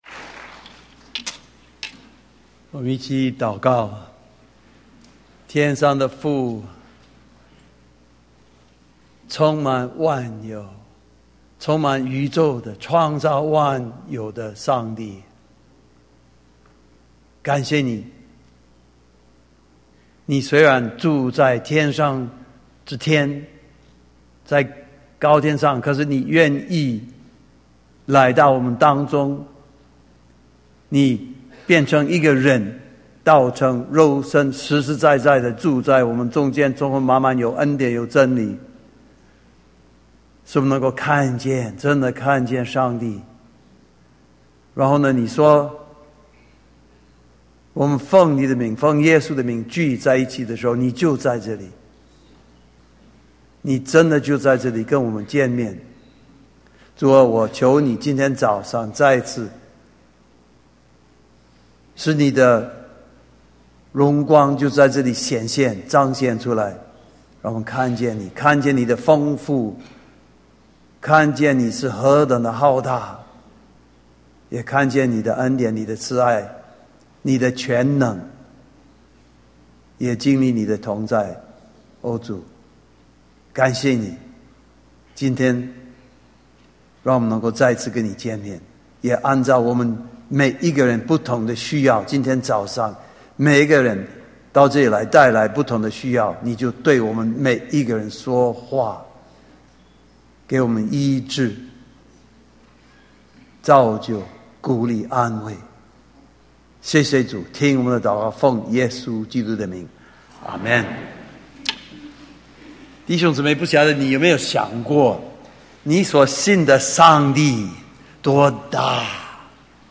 Sermon | CBCGB
Sermon Audio